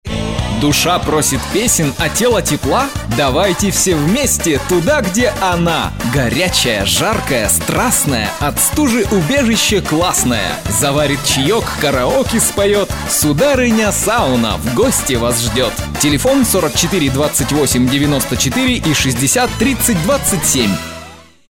Рифмованный текст для аудиоролика (декабрь 2006)